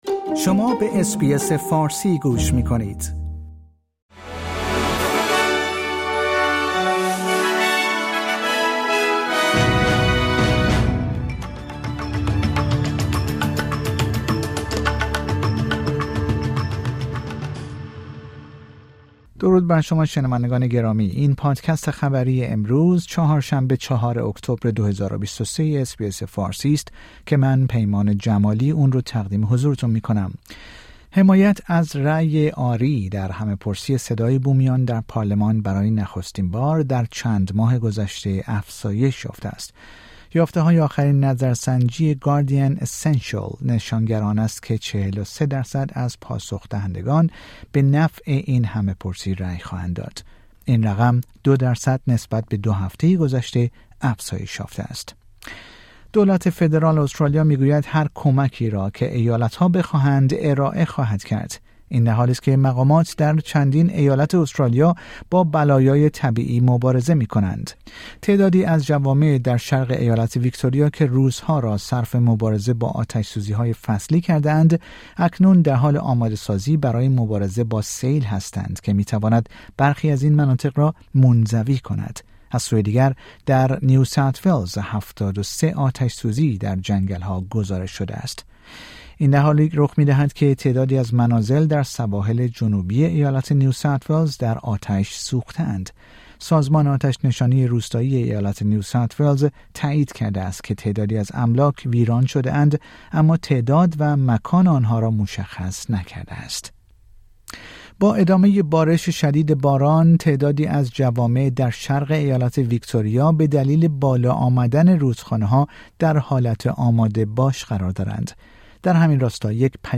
در این پادکست خبری مهمترین اخبار استرالیا و جهان در روز چهارشنبه ۴ اکتبر، ۲۰۲۳ ارائه شده است.